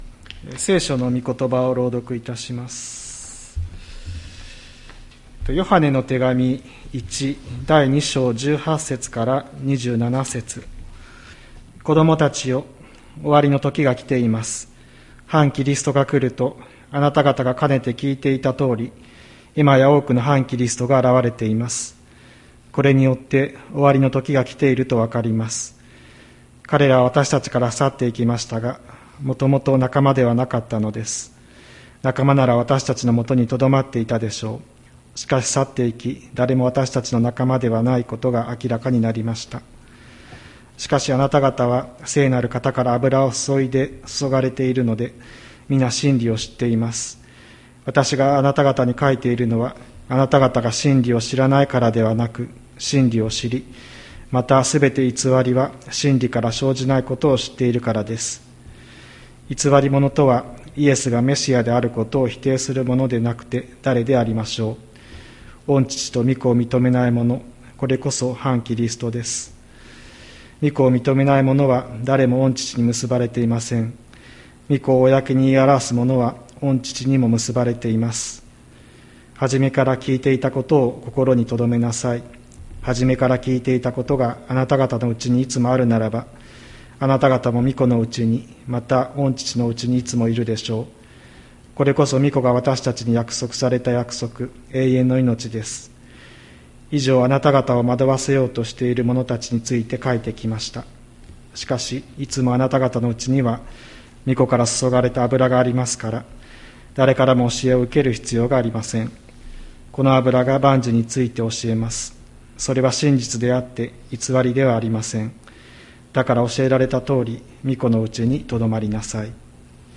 2025年07月27日朝の礼拝「いのちが輝く場所」吹田市千里山のキリスト教会
千里山教会 2025年07月27日の礼拝メッセージ。